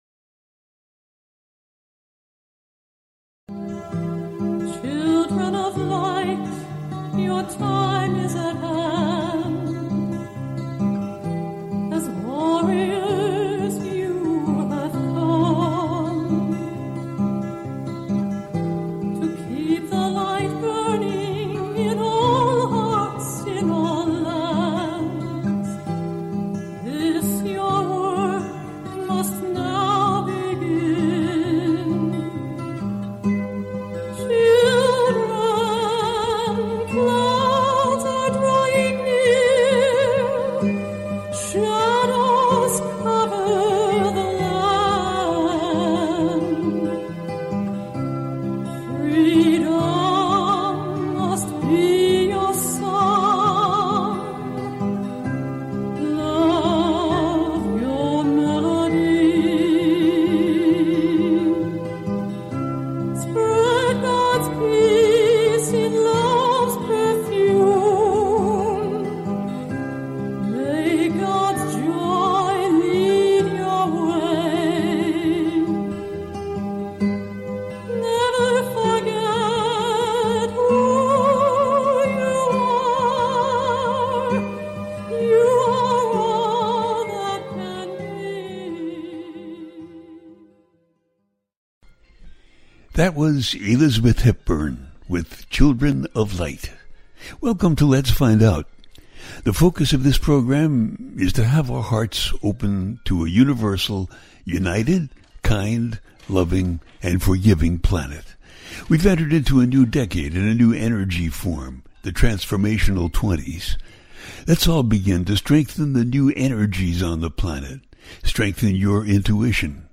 Monthly Call In Show, It's Your Turn For A Spiritual Reading